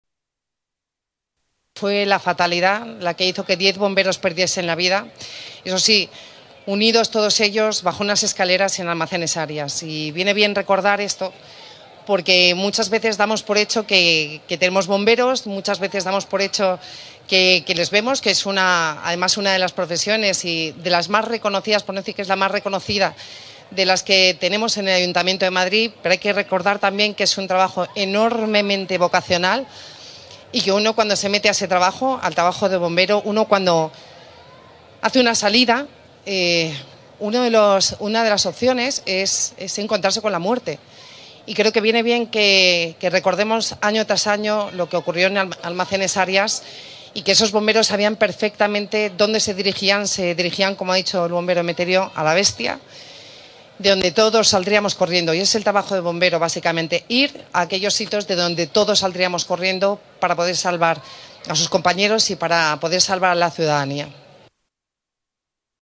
Almeida y Villacís asisten al homenaje a los diez bomberos fallecidos en el incendio de los Almacenes Arias - Ayuntamiento de Madrid
Nueva ventana:Intervención del alcalde, José Luis Martínez-Almeida